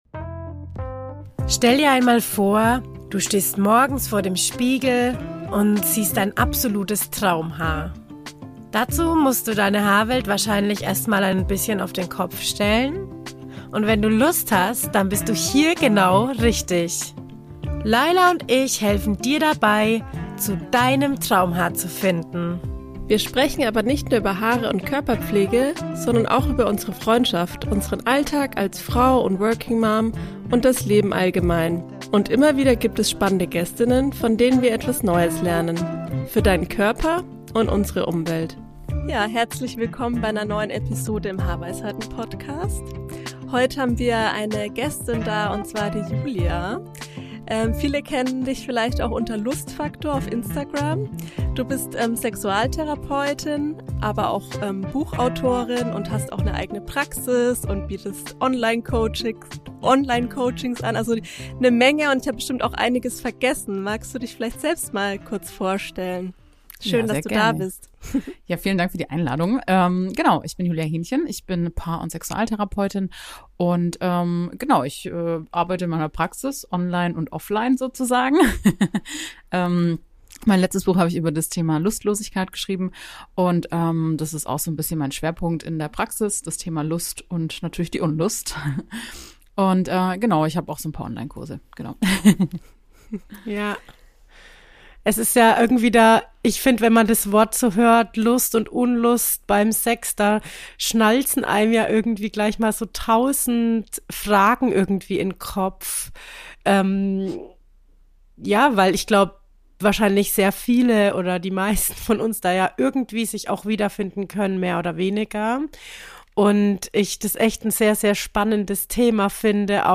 Ein offenes Gespräch über Intimität mit Sexualtherapeuthin